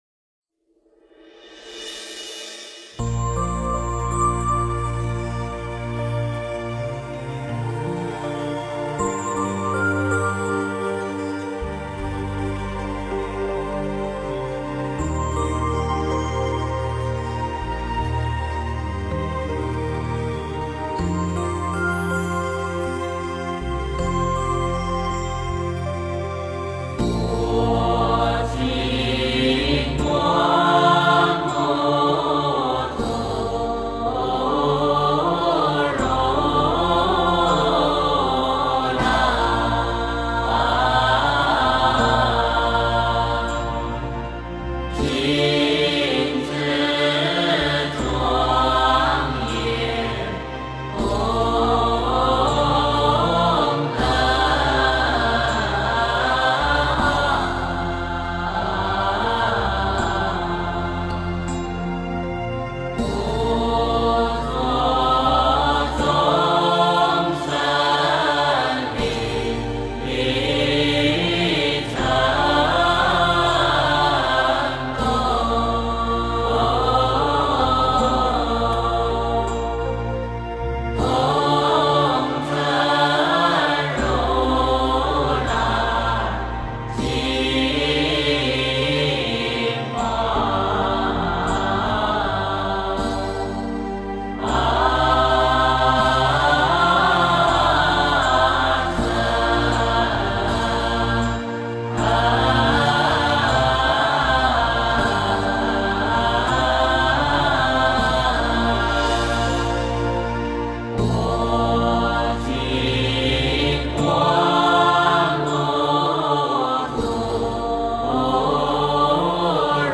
浴佛偈 诵经 浴佛偈--如是我闻 点我： 标签: 佛音 诵经 佛教音乐 返回列表 上一篇： 杨枝净水赞 下一篇： 赞佛偈 相关文章 感恩一切--佛教音乐 感恩一切--佛教音乐...